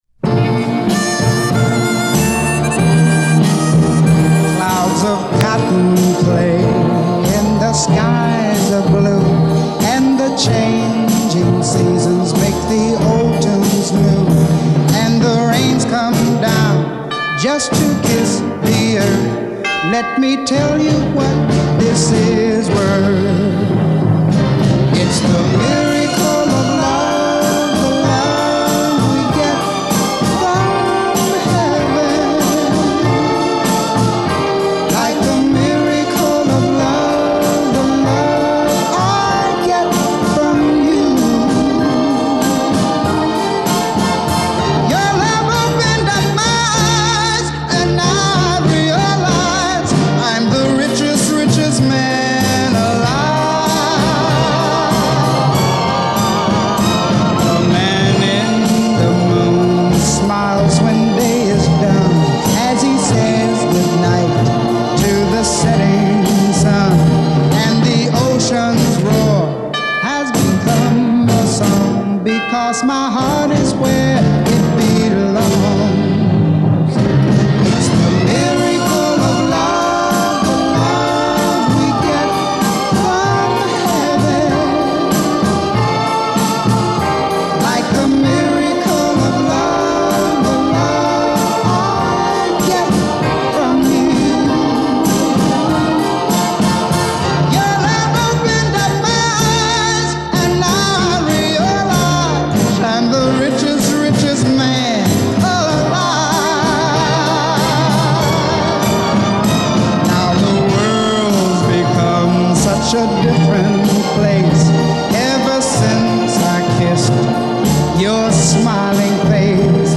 A 60’s Soul powerhouse.